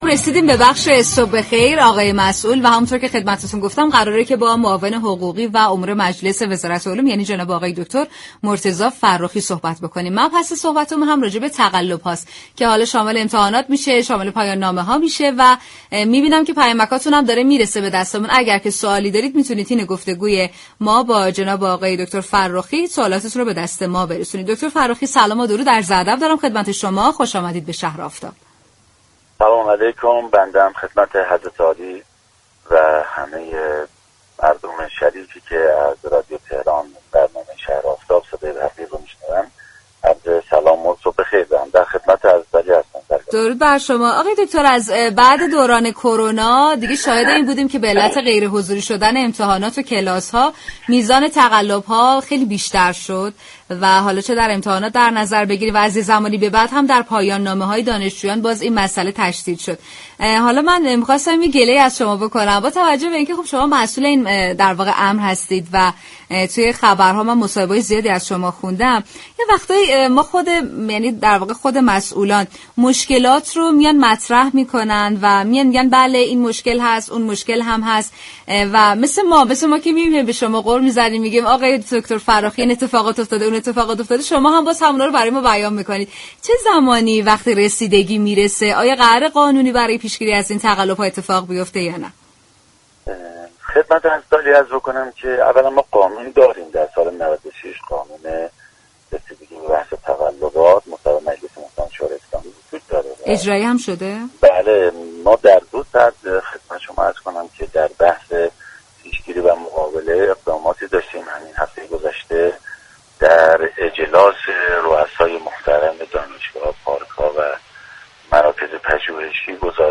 به گزارش پایگاه اطلاع رسانی رادیو تهران،دكتر مرتضی فرخی معاون حقوقی و امور مجلس وزارت علوم، تحقیقات و فناوری در گفت‌و گو با شهر آفتاب رادیو تهران درخصوص تقلب‌های صورت گرفته در امتحان‌ها گفت: قانون پیشگیری و مقابله با تقلب سال 1396 در مجلس به تصویب رسیده است.